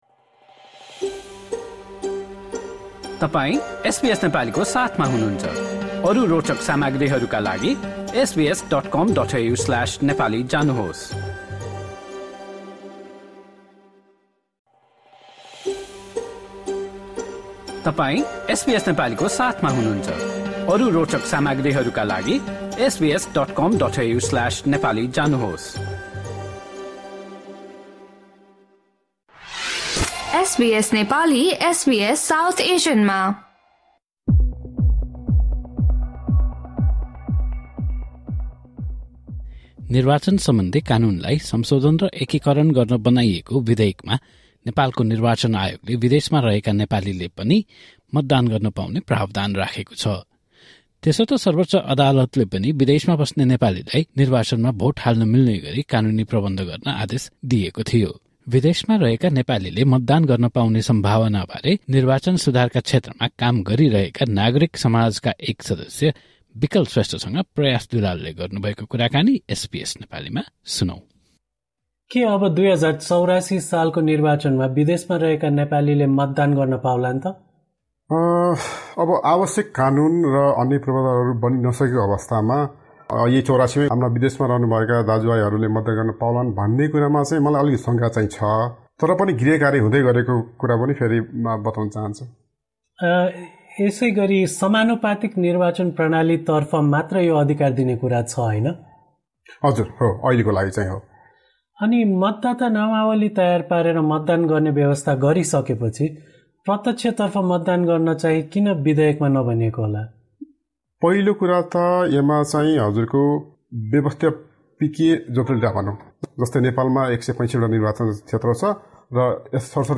spoke to SBS Nepali about the possibilities and challenges to non-resident Nepalis getting voting rights.